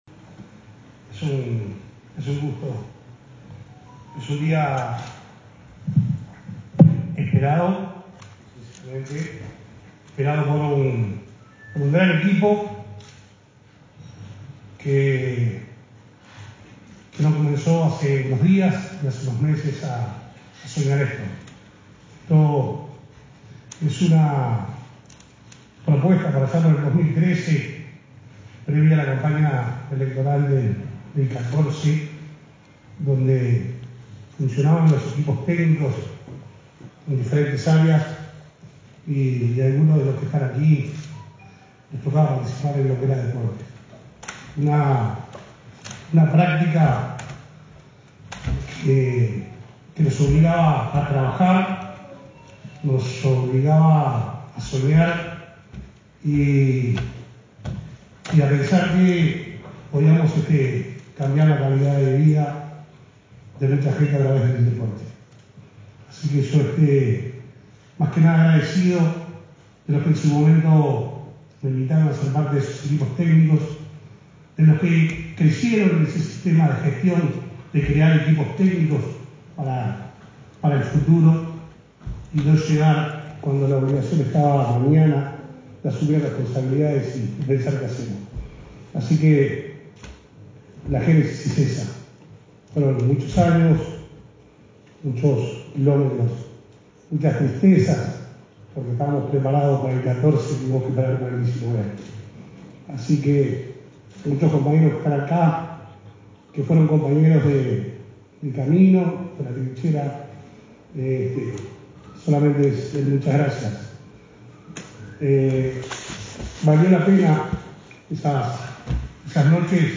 Declaraciones del presidente de ONFI, Eduardo Mosegui
En Flores se realizó el lanzamiento del programa de la Organización Nacional de Deporte Infantil (ONFI), el 19 de agosto. Mosegui participó del acto.